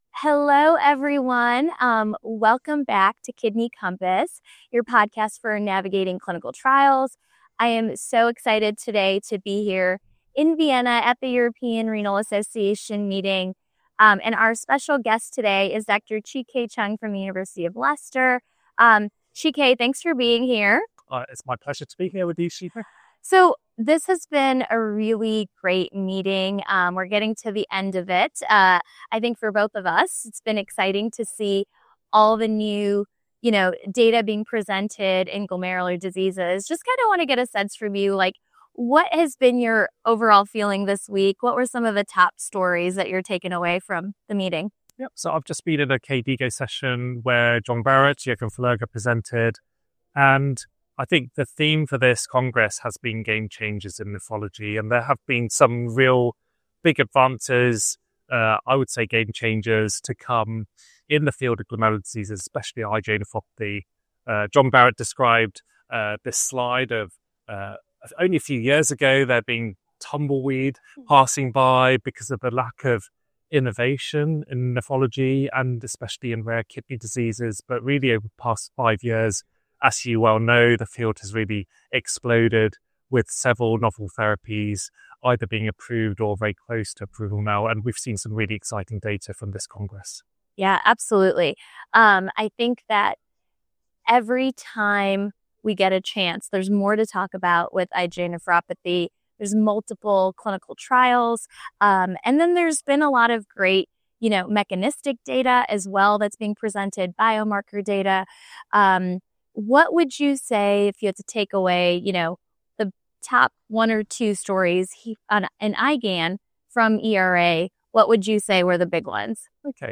At the top of the conversation: the rise of B-cell modulating therapies targeting APRIL and BAFF.